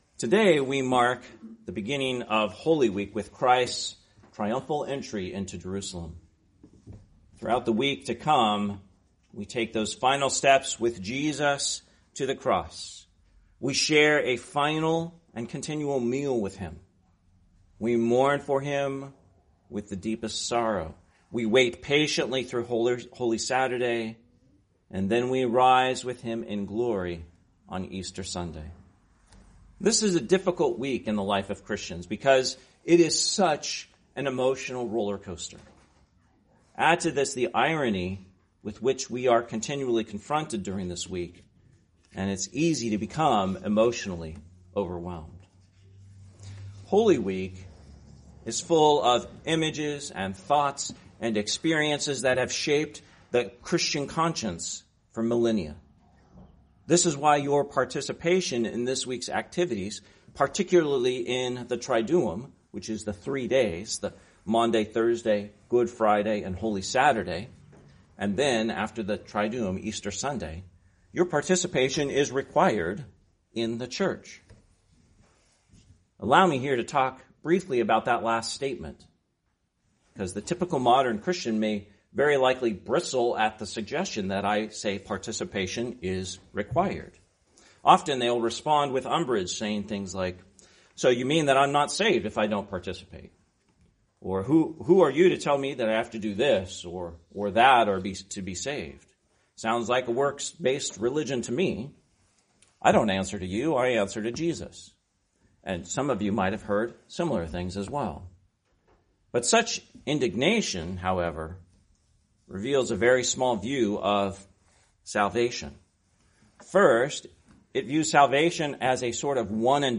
Sermon, Palm Sunday (6th in Lent), 2025 – Christ the King Anglican Church